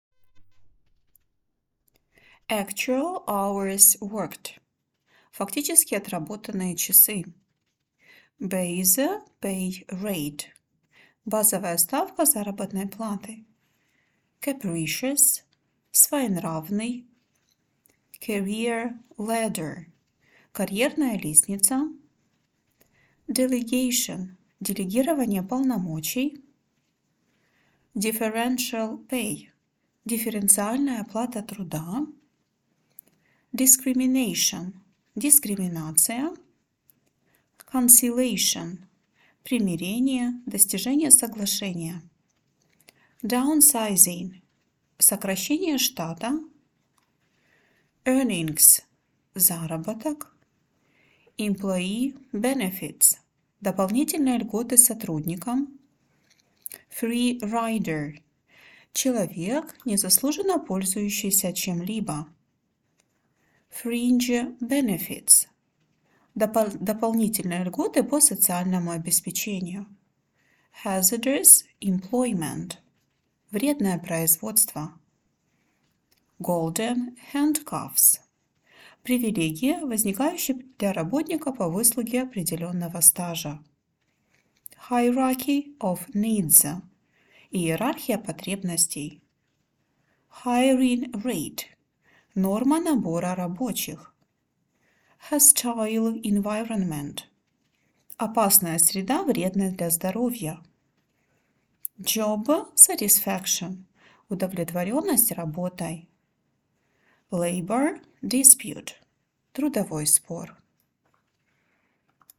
с русским переводом и английской озвучкой